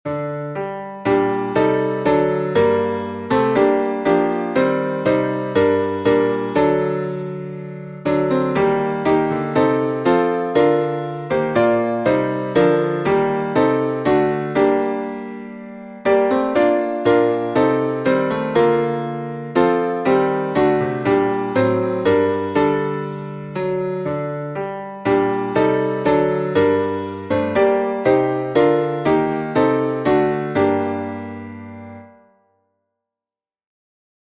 salmo_84B_instrumental.mp3